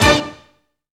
STRING HIT 5.wav